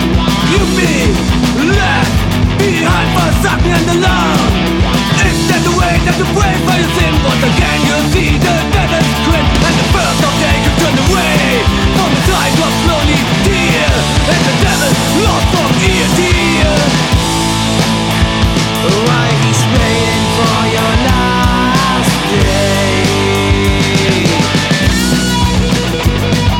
Sooo traurig! Aber laut!